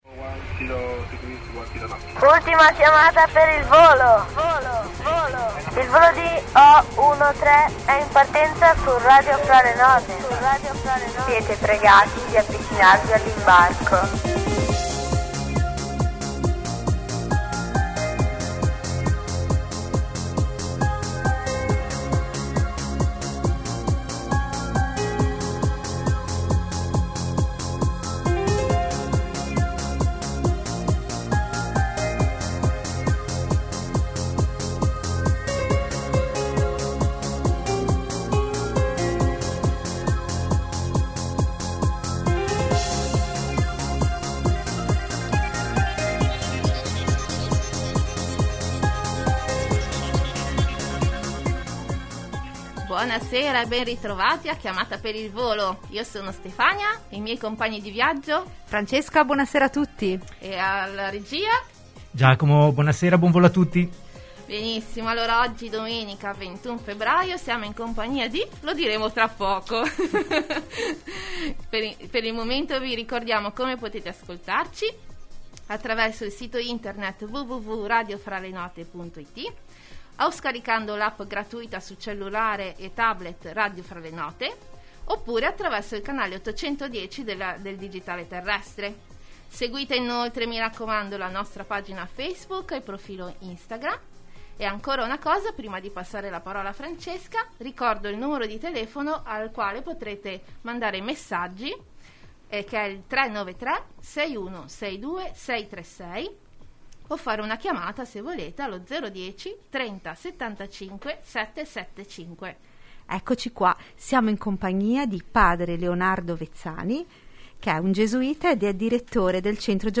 In onda la domenica sera alle 21:00 in diretta dalla Sede centrale di Radio Fra le note in Via Minoretti di Genova